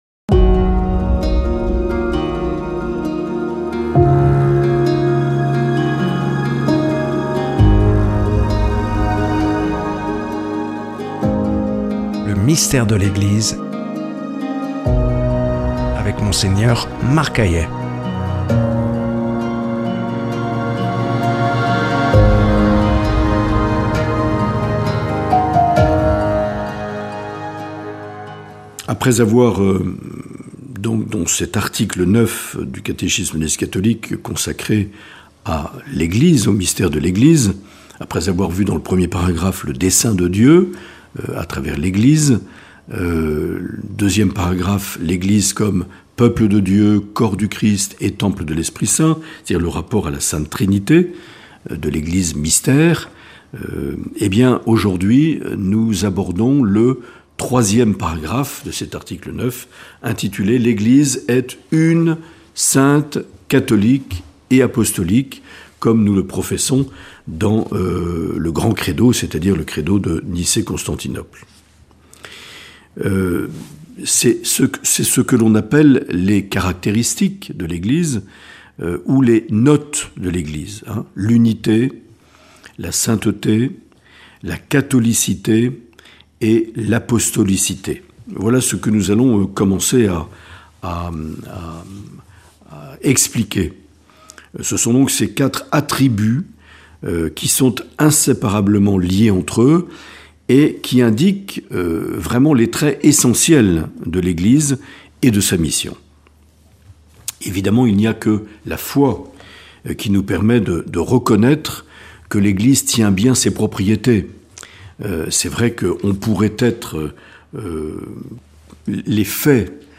Mgr Marc Aillet nous propose une série de catéchèses intitulée "Le Mystère de l’Eglise" notamment à la lumière de la constitution dogmatique "Lumen Gentium" du concile Vatican II.
Une émission présentée par